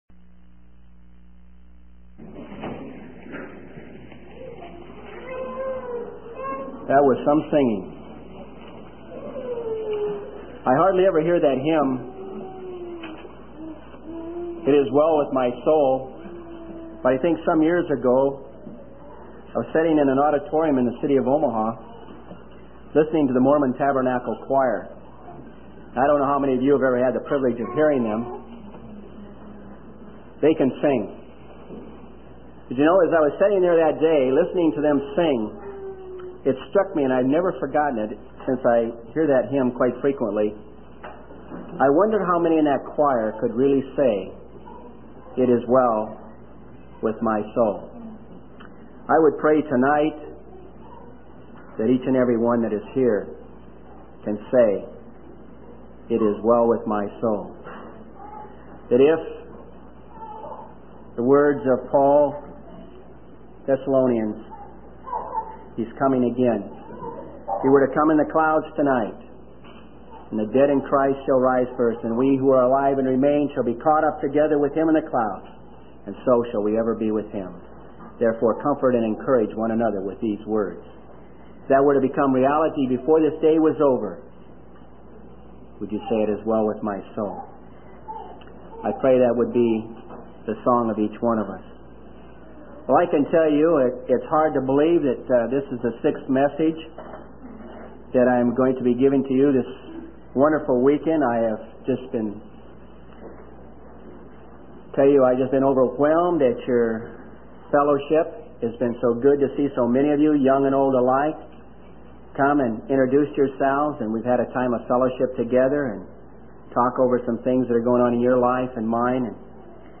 In this sermon, the speaker begins by sharing his enjoyment of a recent float trip and expresses his excitement to share a study on a man who rejected the priesthood of God.